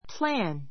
plǽn